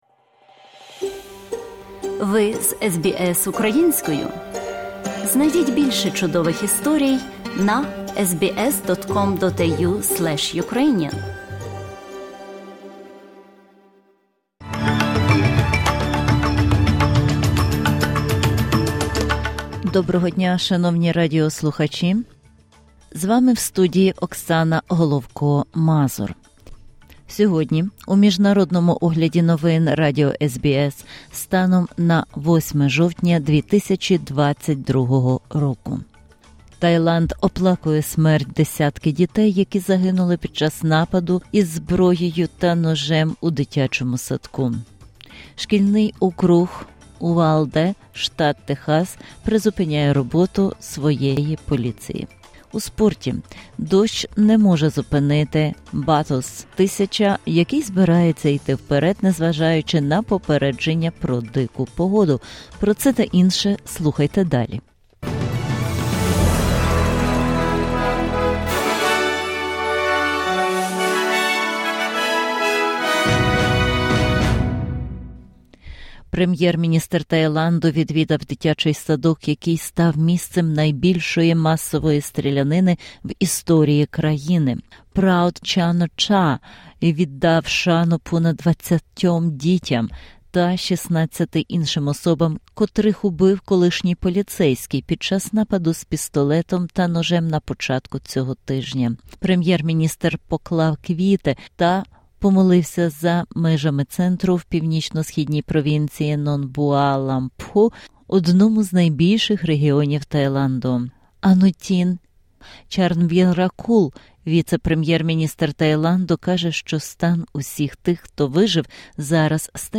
SBS News in Ukrainian - 08/10/2022